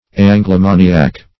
\An`glo*ma"ni*ac\